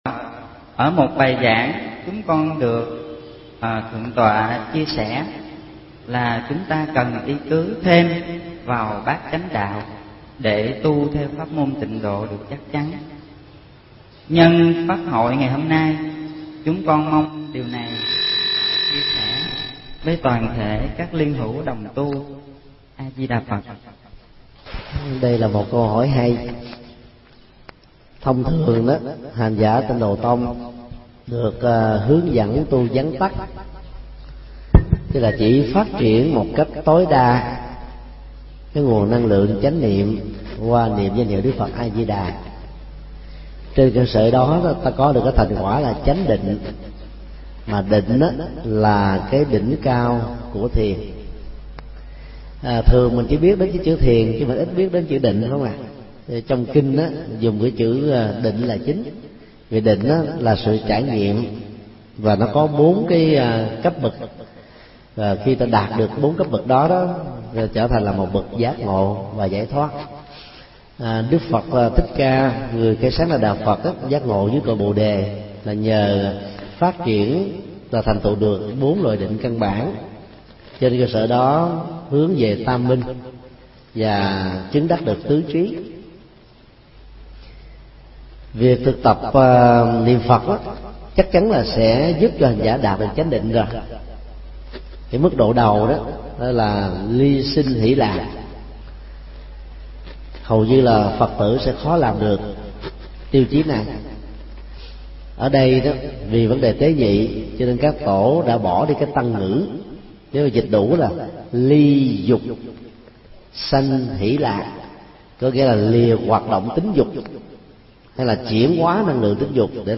Nghe Mp3 thuyết pháp Vấn đáp: Bát chánh đạo – Thích Nhật Từ